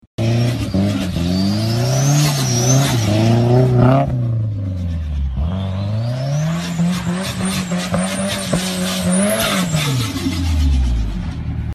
Звуки турбины двигателя
• Качество: высокое
На этой странице вы можете слушать и скачивать онлайн коллекцию реалистичных аудиозаписей: от свиста турбонаддува спортивного автомобиля до оглушительного рева реактивного двигателя.